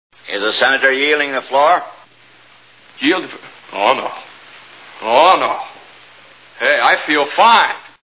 Mr Smith Goes to Washington Movie Sound Bites